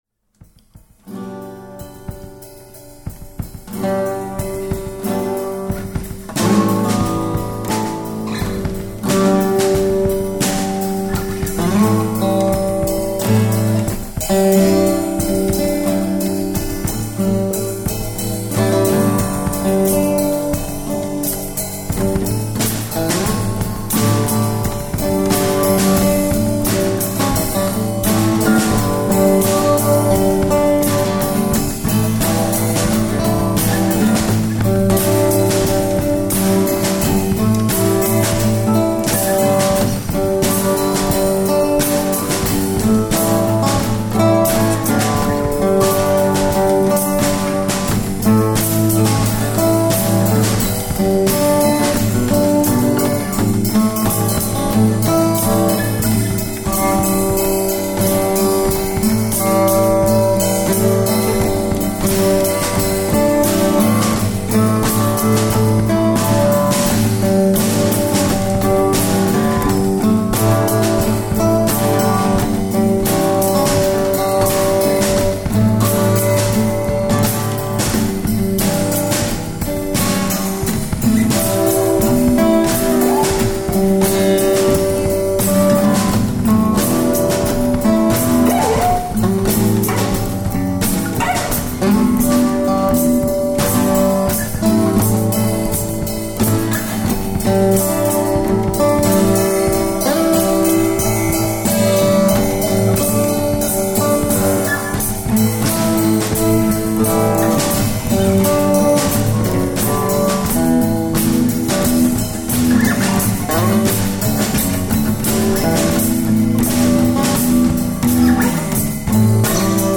This is an instrumental jam scene that came out of me tonight.